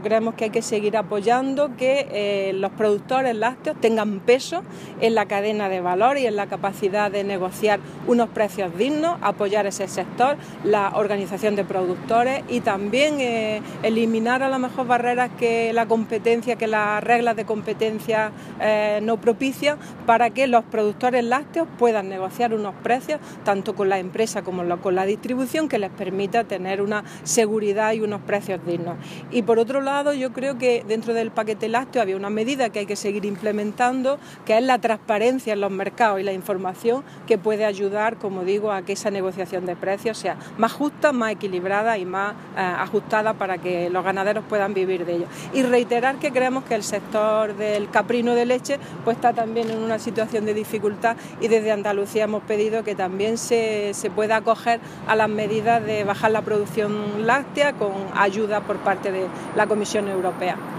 Declaraciones de Carmen Ortiz sobre sector lácteo 2